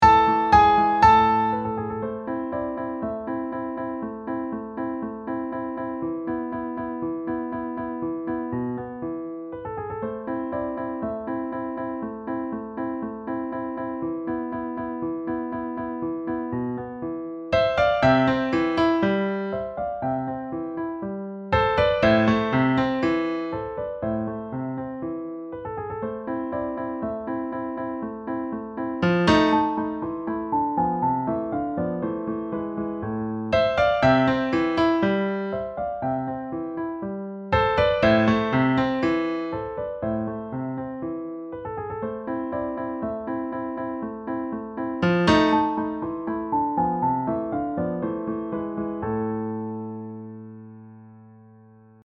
Acompañamiento
Turkis-March-Backtrack.mp3